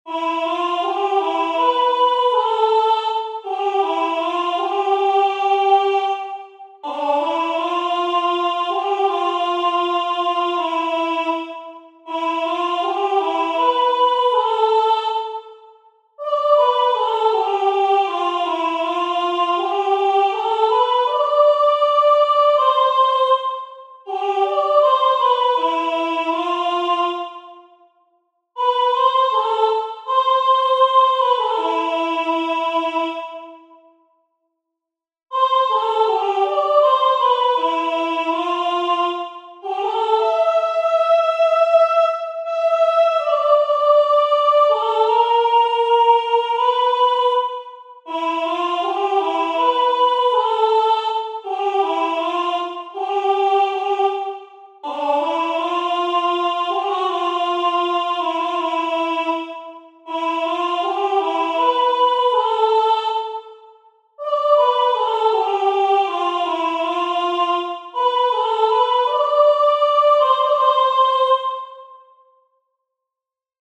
練習曲・休符から始める
曲そのものは休符から始まっていません（笑）八分休符や四分休符その他の長さの休符がフレーズの始まる前に出てきます。